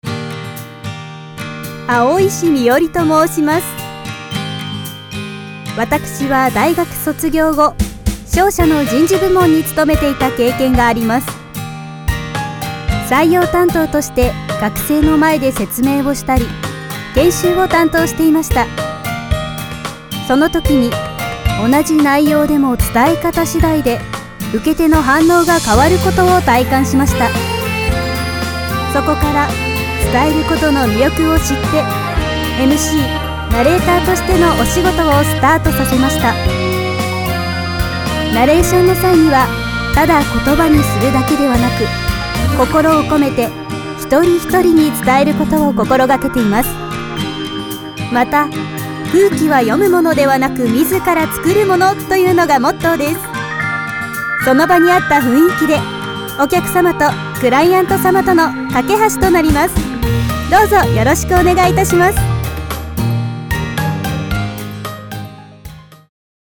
ナレーター｜MC｜リポーター